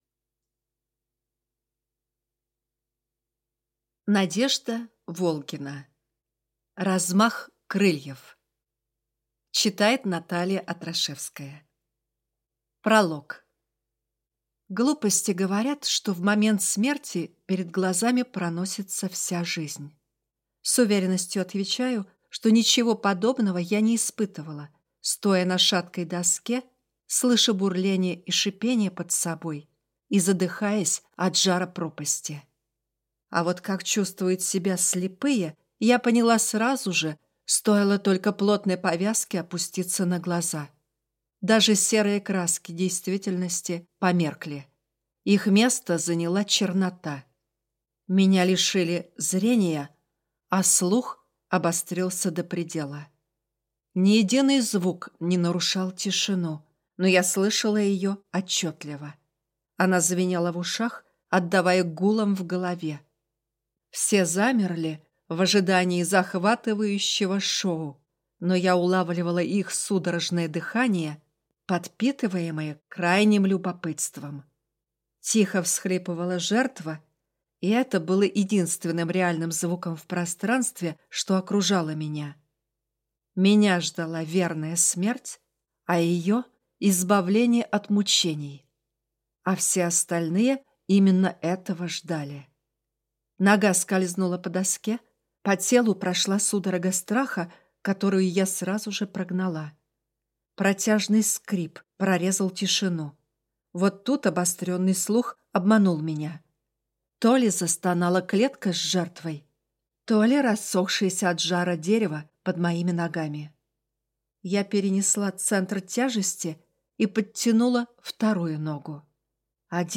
Аудиокнига Размах крыльев | Библиотека аудиокниг